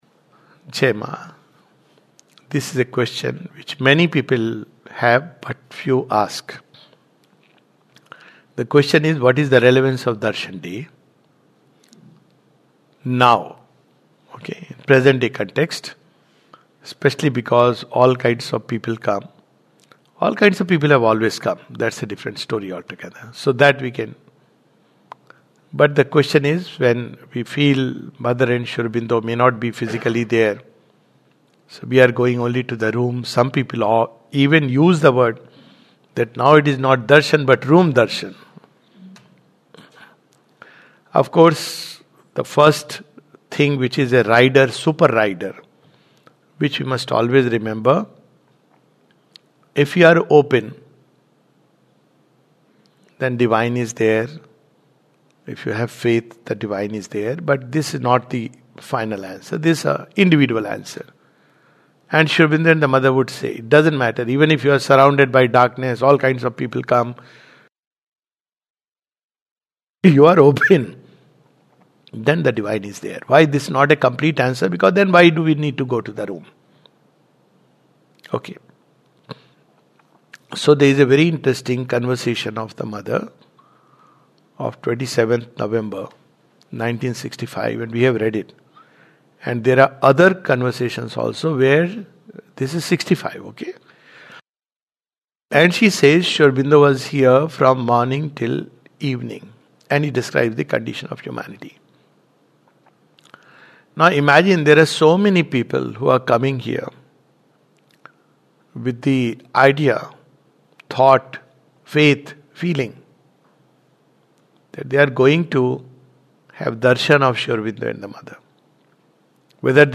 This talk is about the relevance of visiting Sri Aurobindo and the Mother's room.